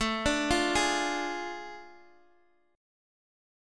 Ddim/A chord
D-Diminished-A-x,x,7,7,6,4-8.m4a